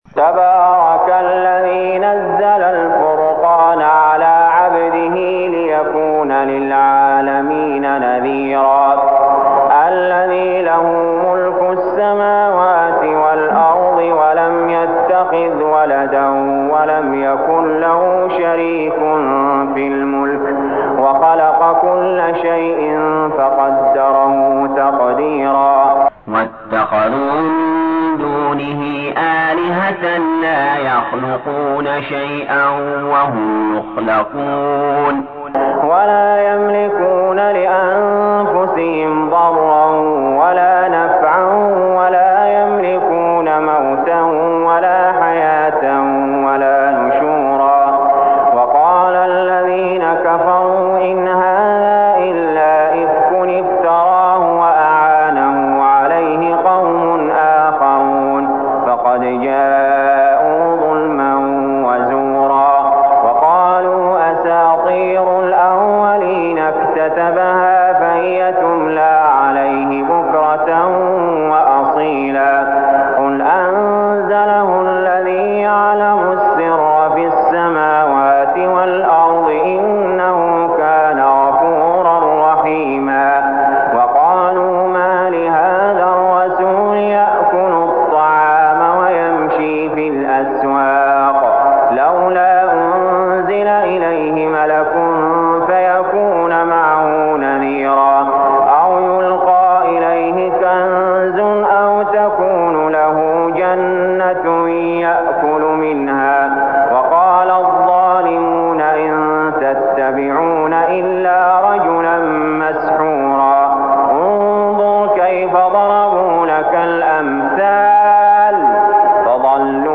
المكان: المسجد الحرام الشيخ: علي جابر رحمه الله علي جابر رحمه الله الفرقان